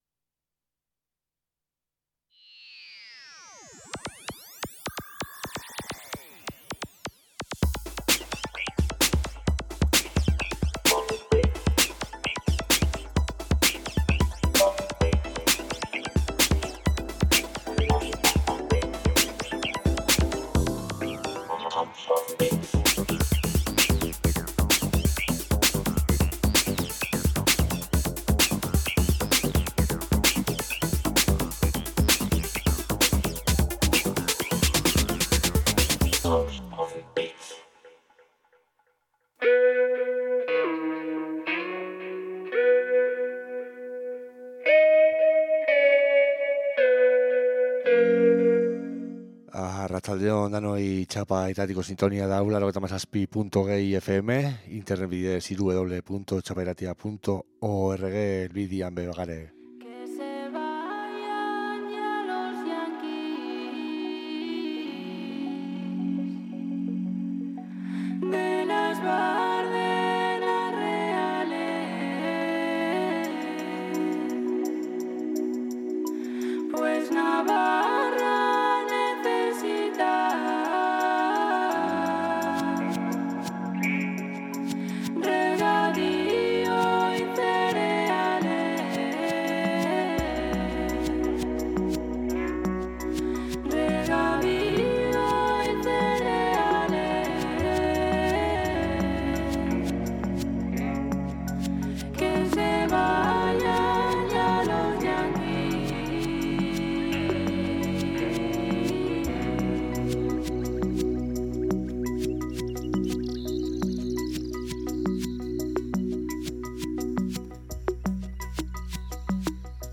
Txapa irratian elektronika kultura sustatzen duen irratsaioa. Elkarrizketak, sesioak, jai alternatiboen berriak eta musikaz gozatzeko asteroko saioa.